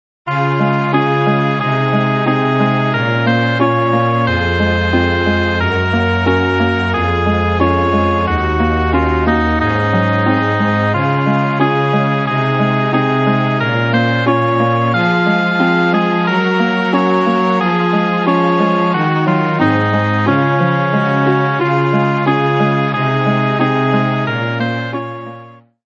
023 　のどかな感じ3（C#） 06/08/09